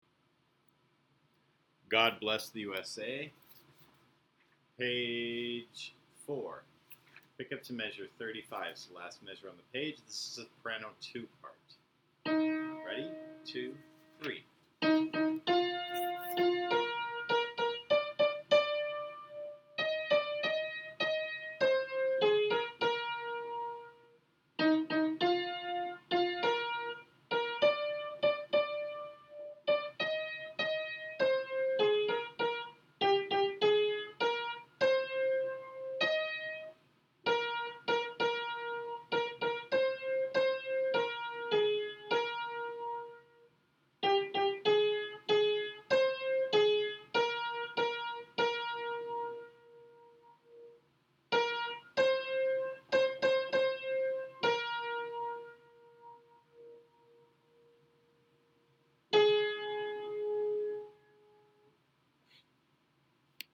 Soprano 2 – middle notes